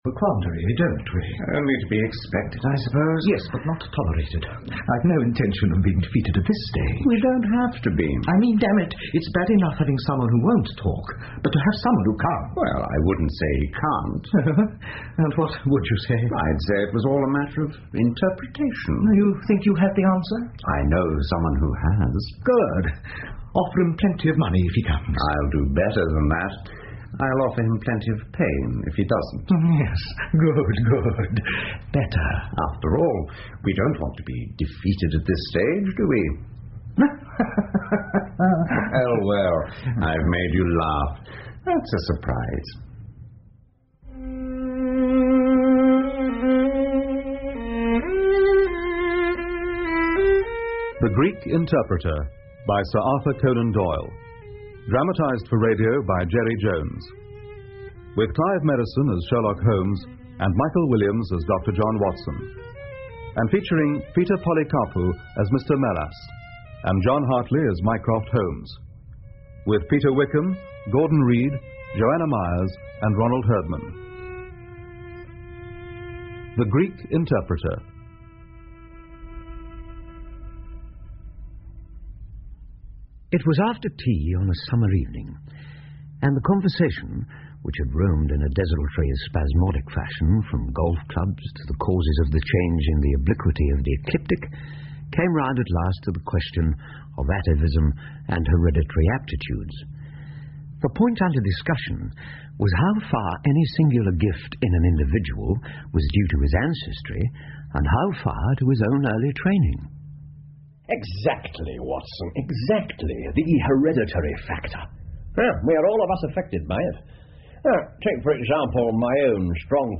福尔摩斯广播剧 The Greek Interpreter 1 听力文件下载—在线英语听力室